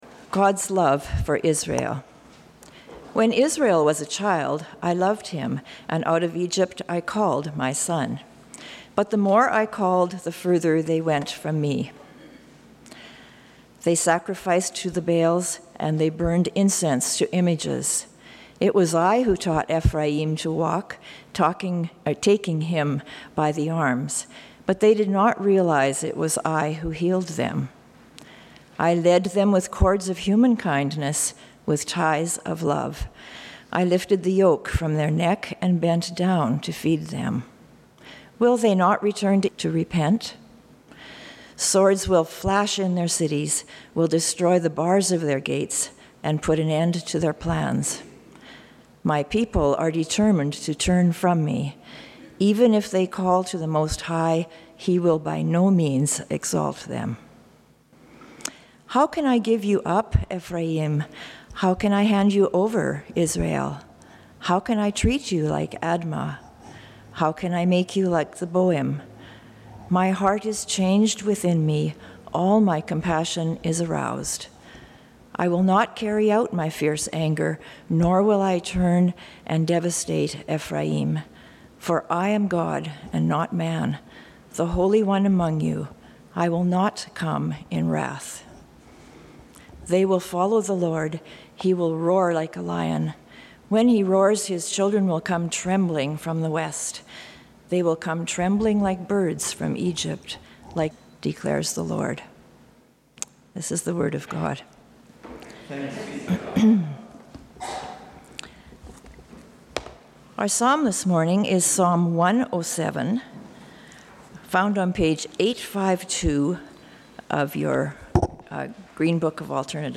Sermon Notes
There was a slight technological fail on Sunday, August 4 and the last part of the sermon got cut off.